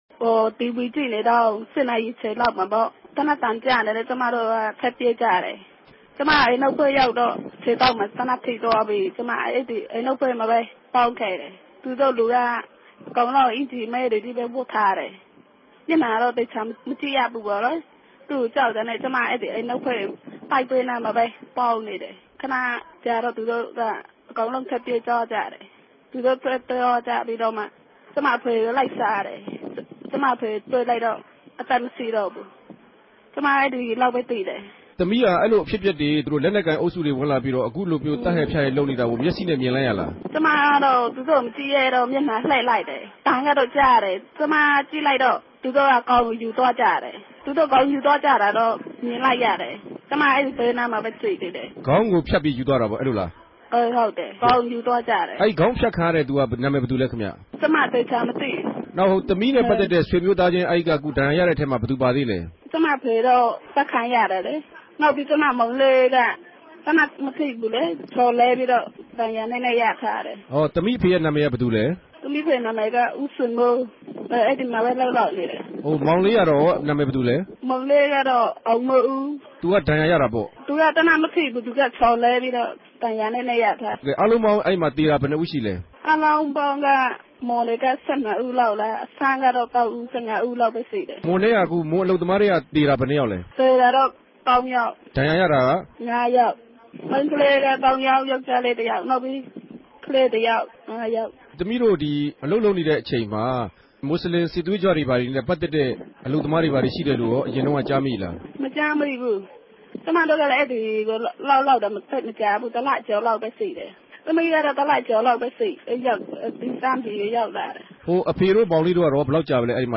ထိုင်းိံိုင်ငံ တောင်ပိုင်းမြာ မတ်လ ၁၁ရက် တနဂဿေိံြနေႛက မစြလင် ဘာသာရေးအစြန်းရောက် လက်နက်ကိုင်တေရြဲ့ ပစ်ခတ်မြေုကာင့် ူမန်မာအလုပ်သမား သုံးဦး သေဆုံးသြား္ဘပီး ဒဏ်ရာရသြားသူတေလြည်း ရြိပၝတယ်၊ သေနတ်ဒဏ်ရာနဲႛ ဆေး႟ုံတက်နေရတဲ့ မြန်အမဵိြးသမီးတဦးကို RFAက ဆက်သြယ် မေးူမန်းထားပၝတယ်။